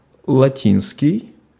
IPA : [ˈlæt.ən]